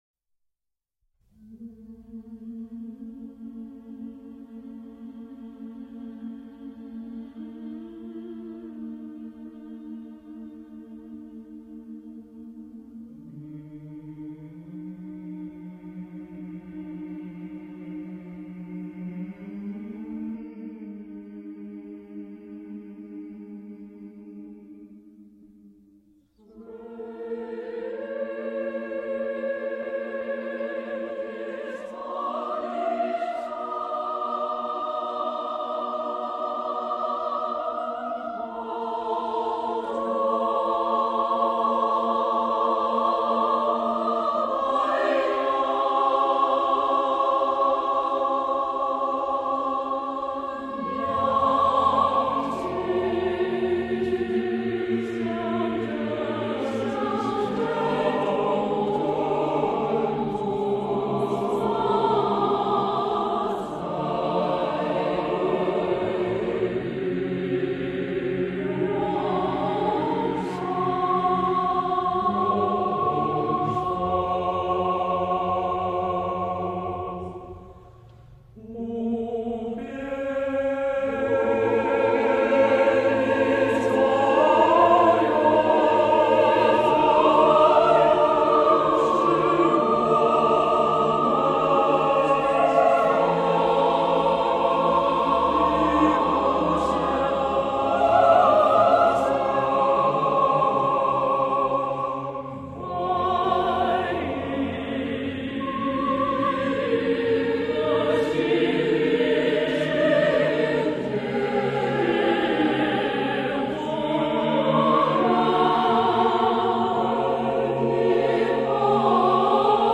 东蒙民歌